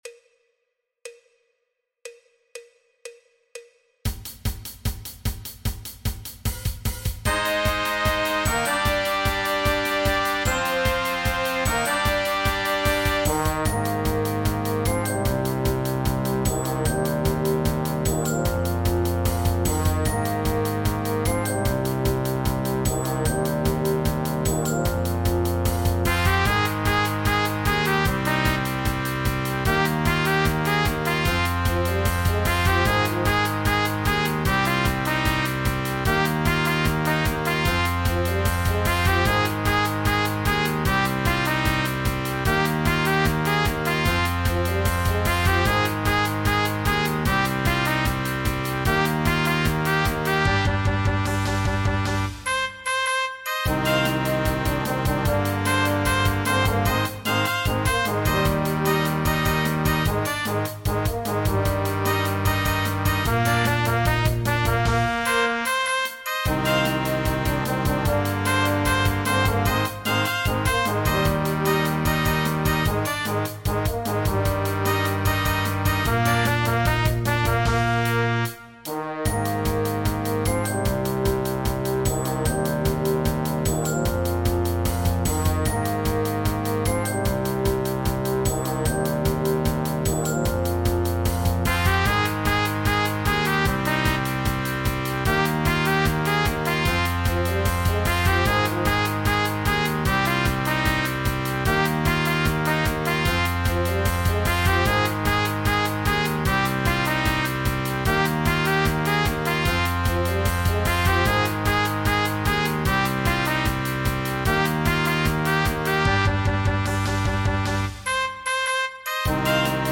druckvoll, melodiös und mitreissend.
Gesamtschwierigkeit: Einfach